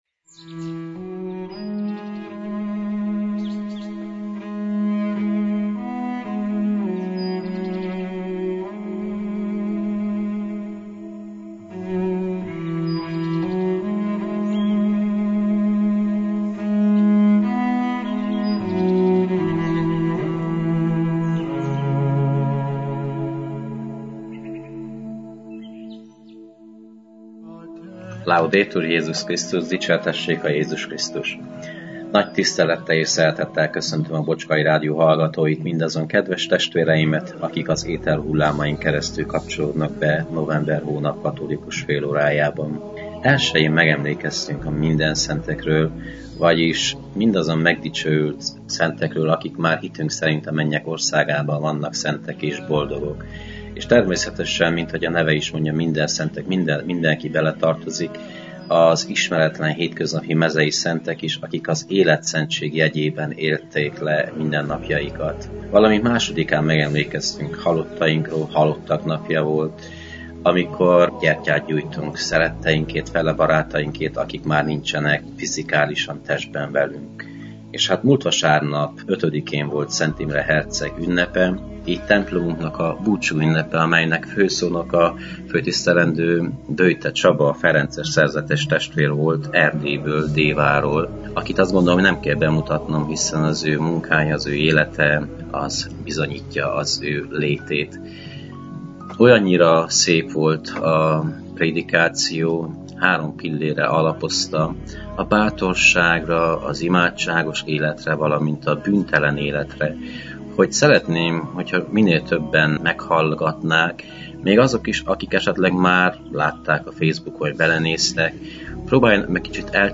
Igét hirdet Böjte Csaba ferences rendi atya, a clevelandi Szent Imre Katolikus Templomból.